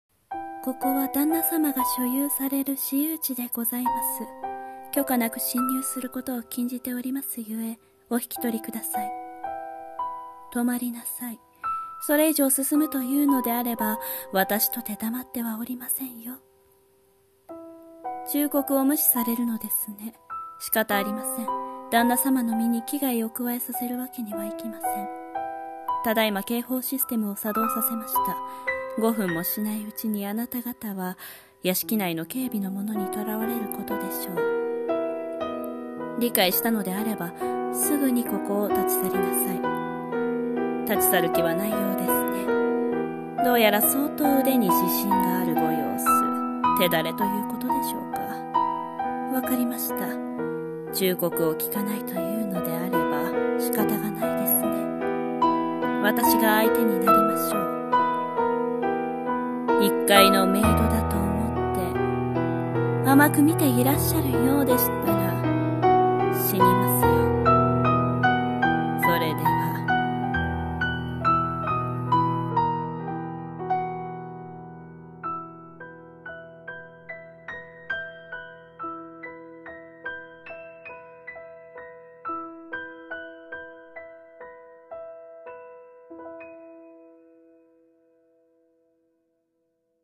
声劇【メイド】